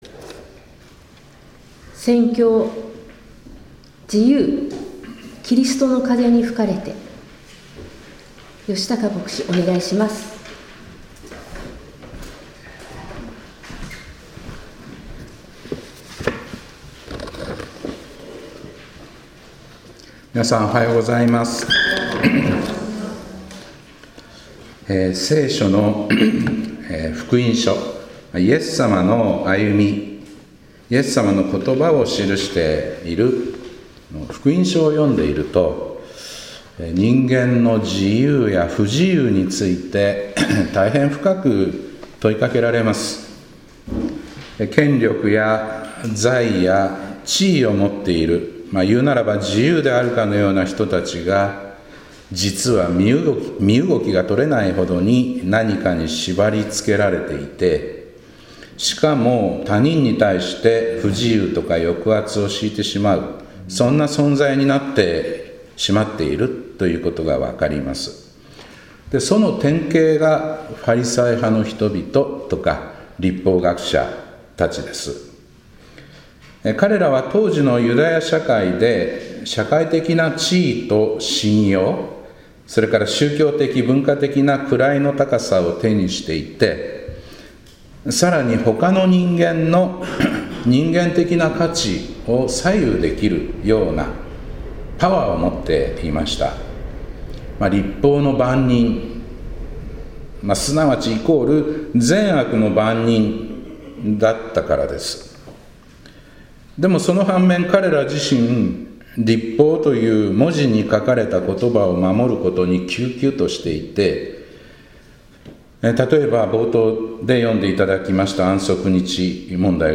2025年5月25日礼拝「自由～キリストの風に吹かれて～」